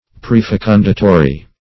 Prefecundatory \Pre`fe*cun"da*to*ry\